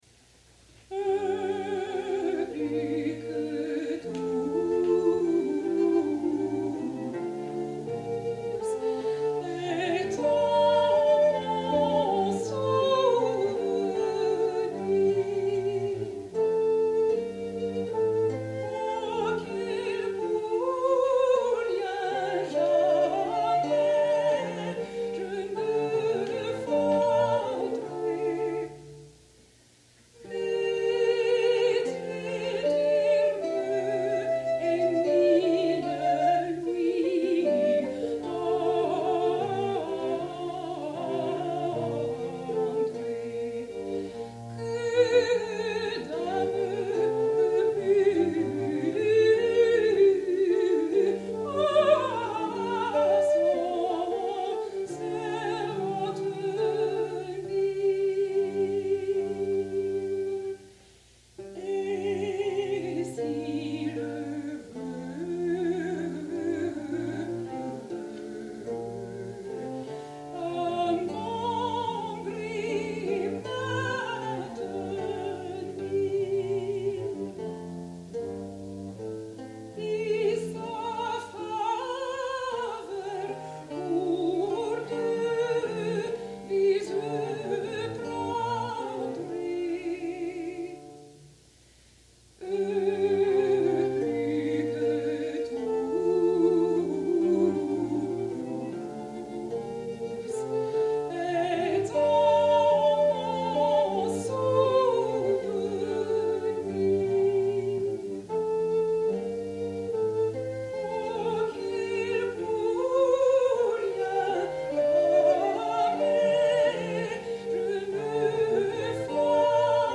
Chamber Consort
soprano
viol
recorder
lute.